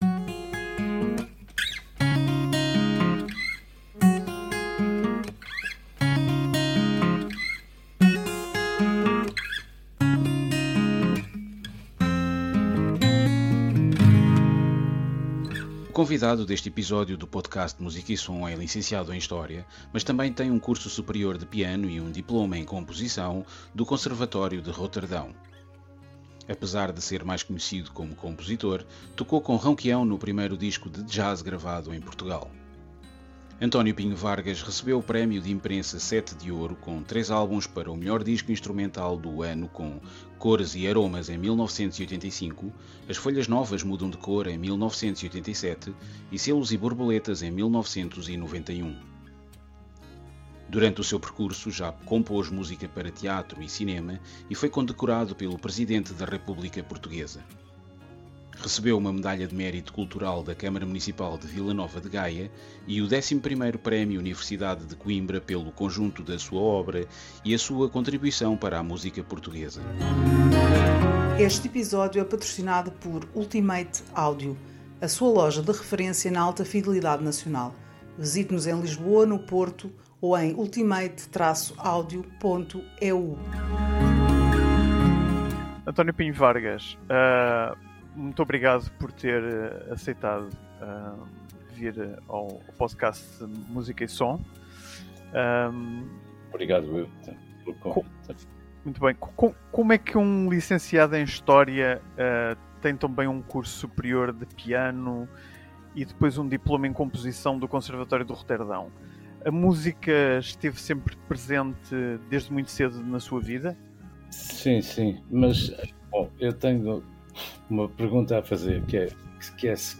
Mais do que à primeira vista seria de supor, como nos conta numa entrevista onde revela algumas facetas menos conhecidas da sua vida e carreira musical.